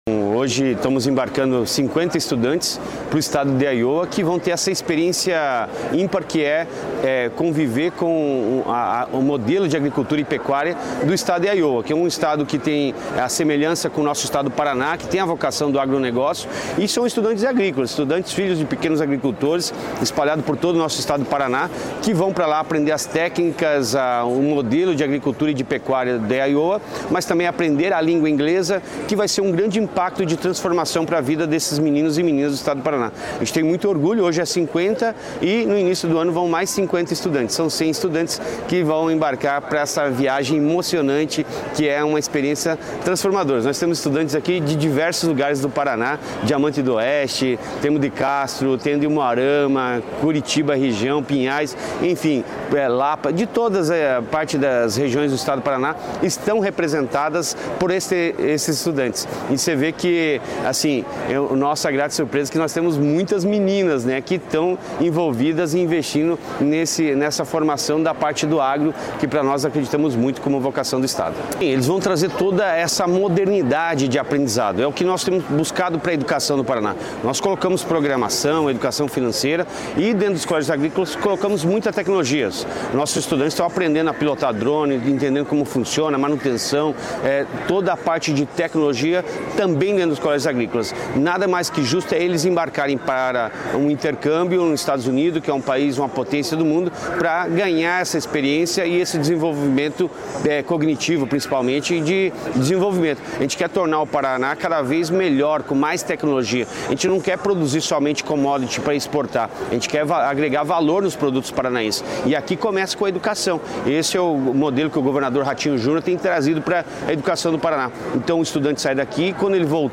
Sonora do secretário Estadual da Educação, Roni Miranda, sobre o embarque de 50 estudantes para os EUA pelo Ganhando o Mundo Agrícola